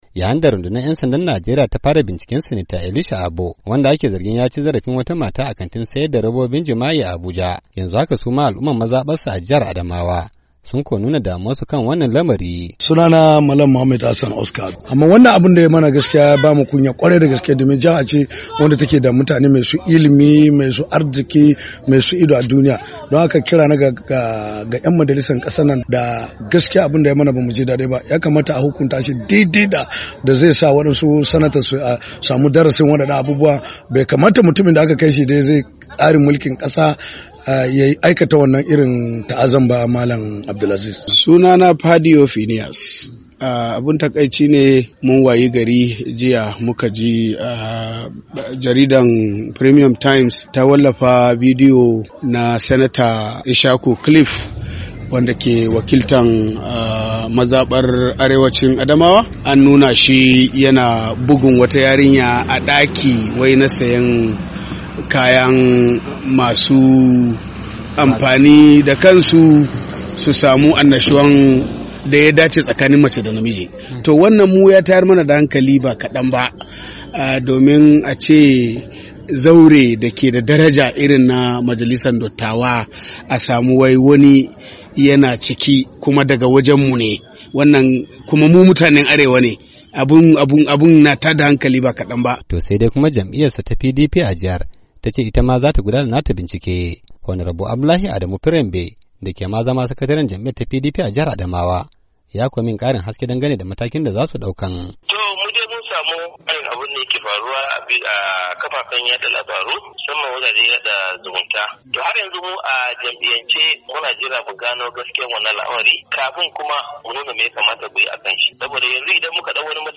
Ga cikakken rahotun wakilin Muryar Amurka